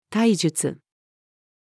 体術-female.mp3